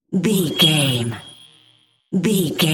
Sound Effects
Atonal
magical
mystical
special sound effects